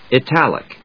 音節i・tal・ic 発音記号・読み方
/ɪtˈælɪk(米国英語)/